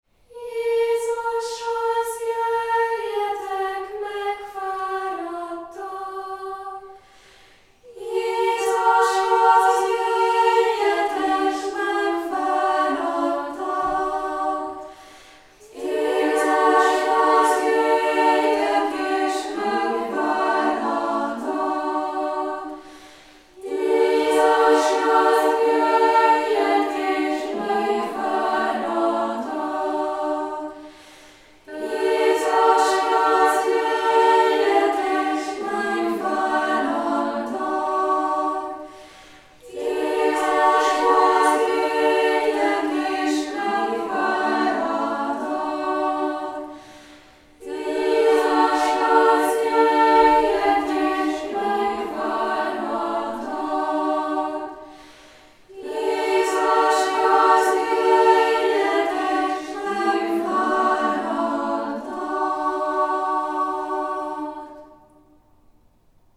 Dallam: Általánosan ismert német dallam.
Ezzel a gyermeki hittel énekelhetjük ma is az általánosan elterjedt egyszerű dallamot, és így alkothatunk kórust a kánonéneklésben: átélve a közösség harmóniát adó erejét.